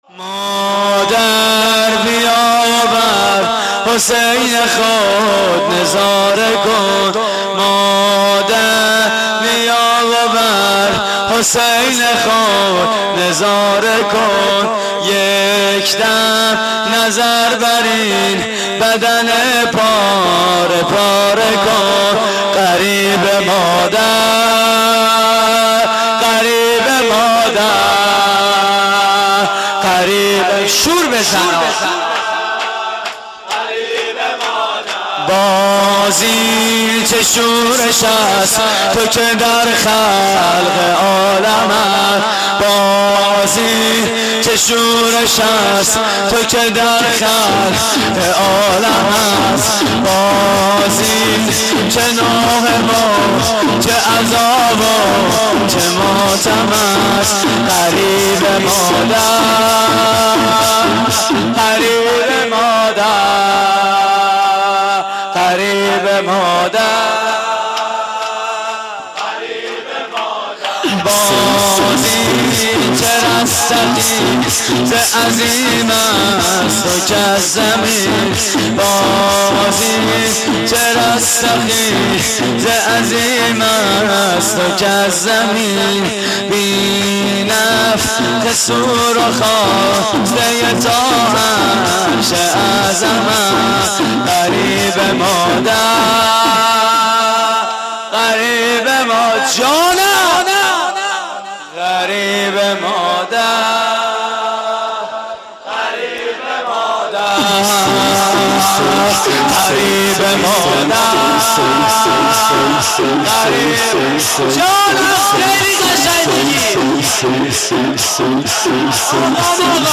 شور: مادر بیا و بر حسین خود نظاره کن
مراسم عزاداری شب عاشورای حسینی